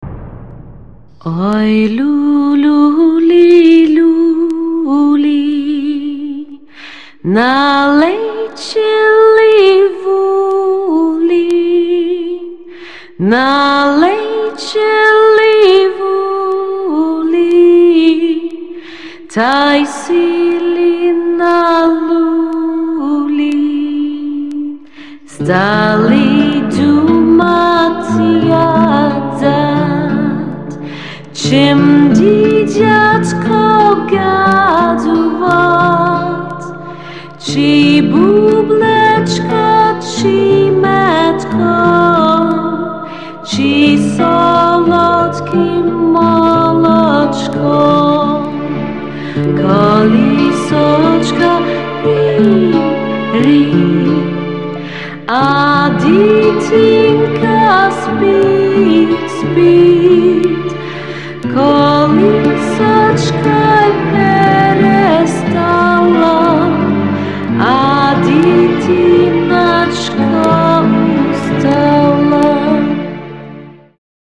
Колыбельная на украинском языке 🇺🇦
«Ой, люлі» - это старинная украинская колыбельная.